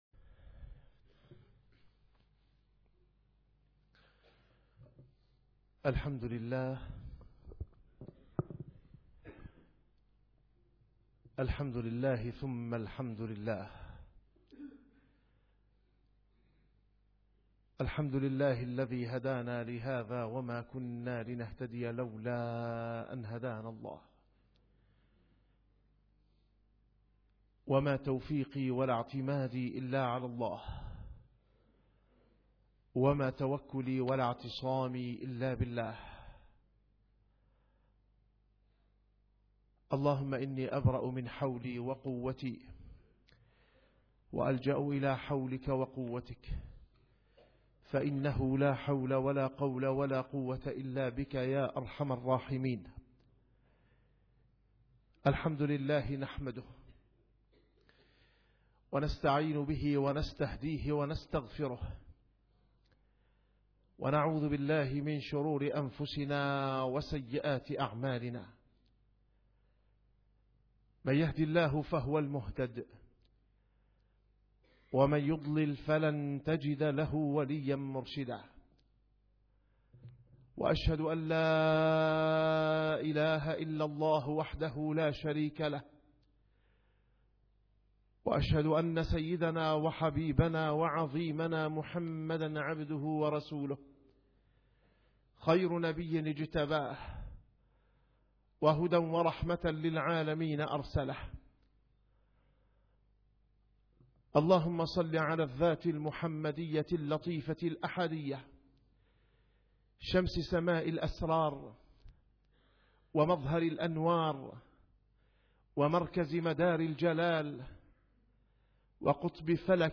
- الخطب - منهج وجداني لحج رباني